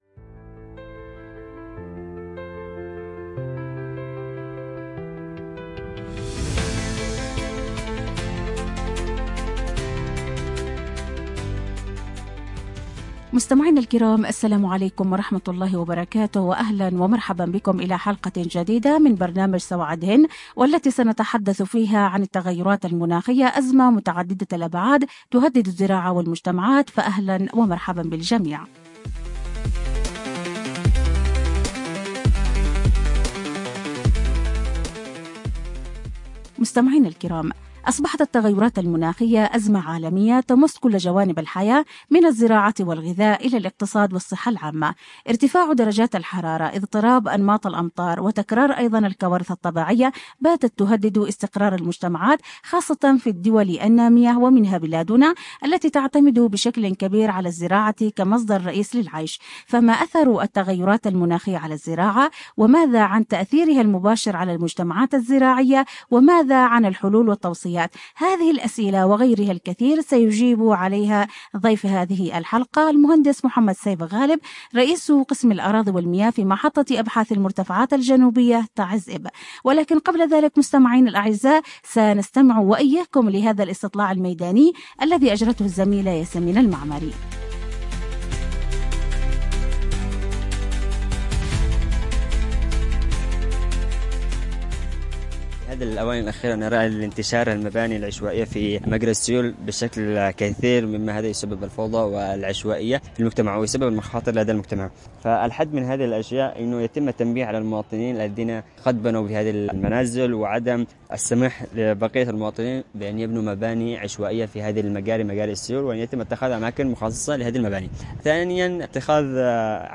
عبر أثير إذاعة رمز